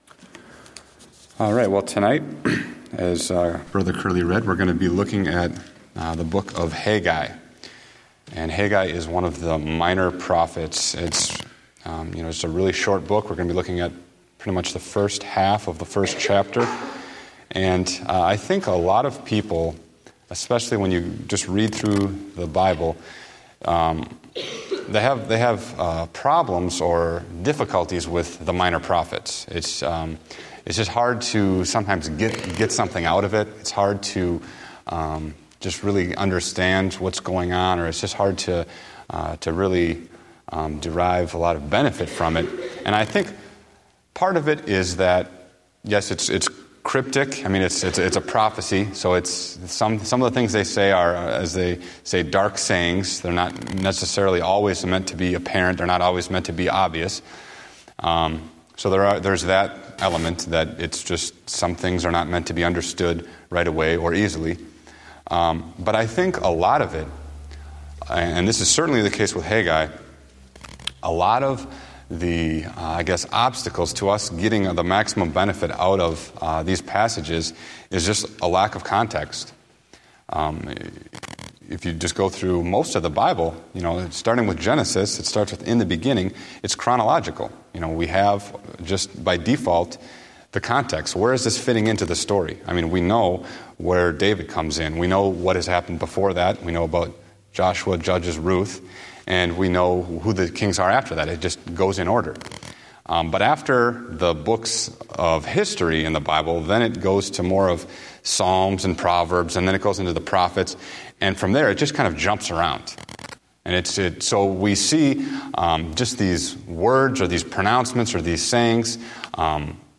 Date: December 28, 2014 (Evening Service)